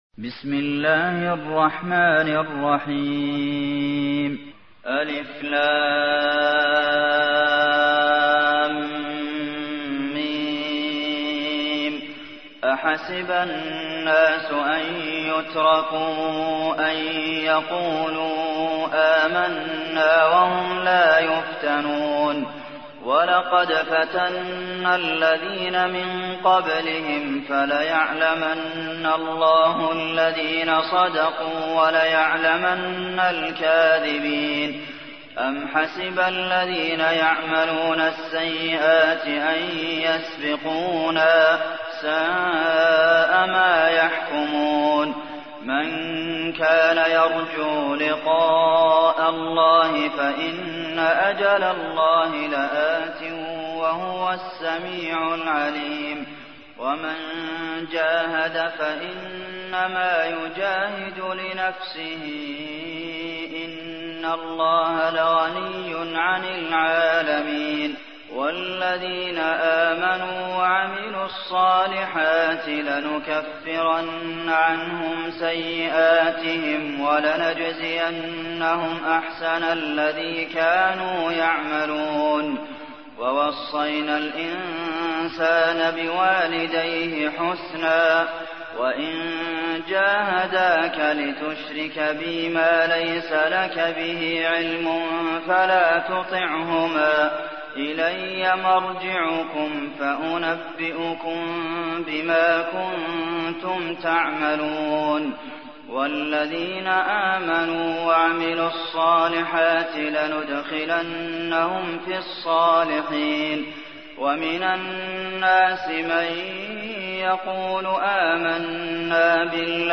تحميل : 29. سورة العنكبوت / القارئ عبد المحسن قاسم / القرآن الكريم / موقع يا حسين